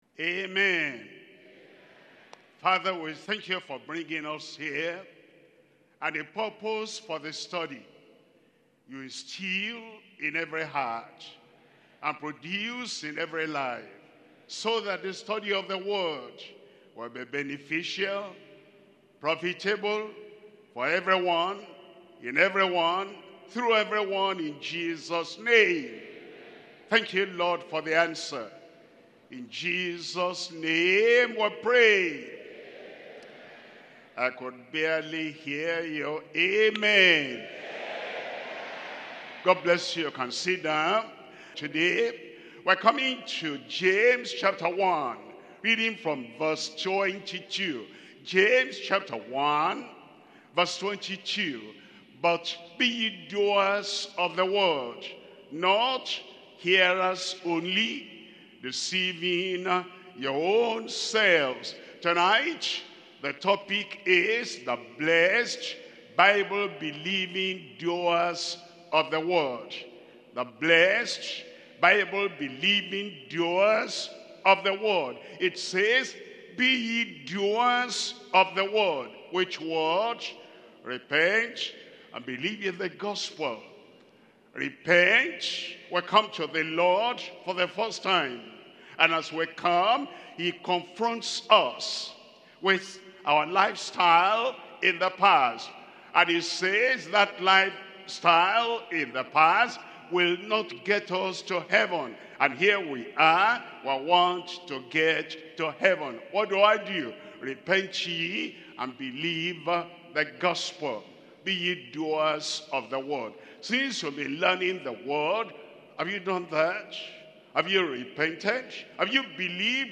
Sermons - Deeper Christian Life Ministry
2025 Workers Training